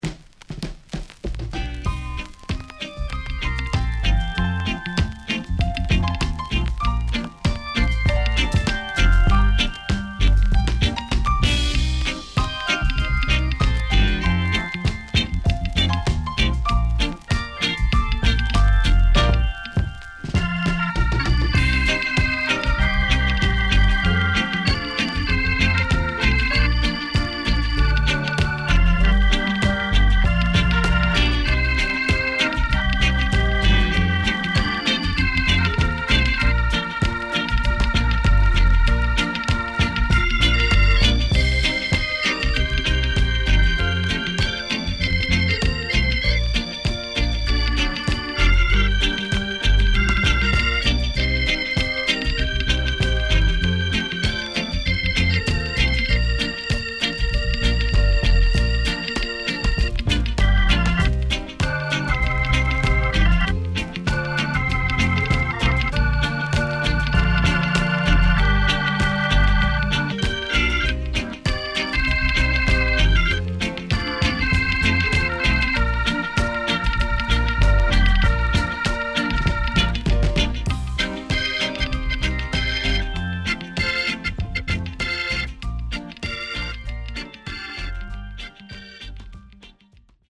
Good Vocal & Mellow Organ Inst.W-Side Good
SIDE A:軽いヒスノイズがあり、少しプチノイズ入ります。